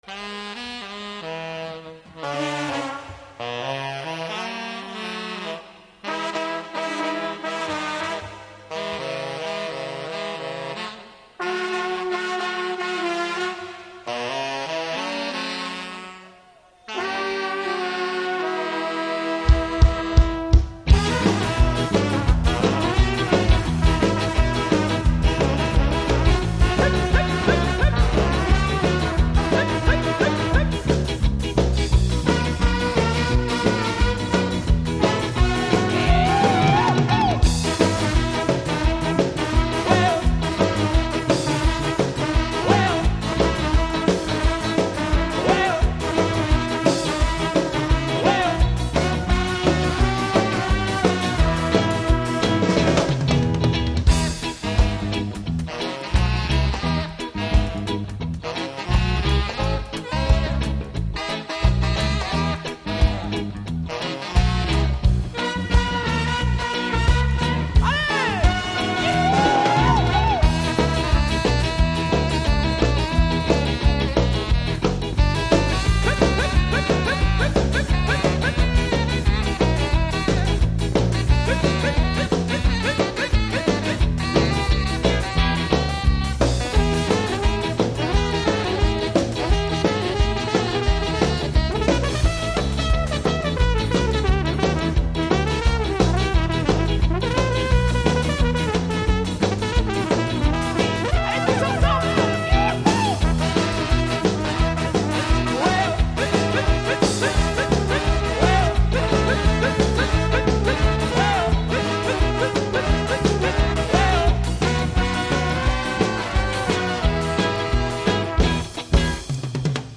EXTRAITS LIVE INEDITS (prises directes concerts)